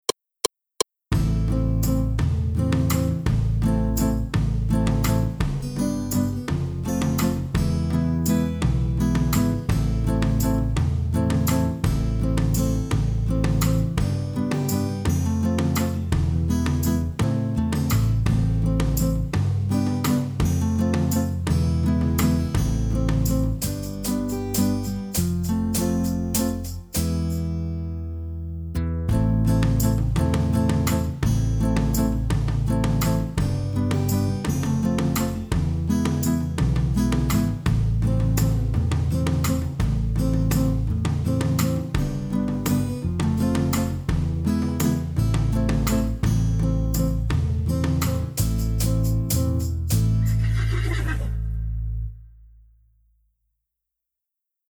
반주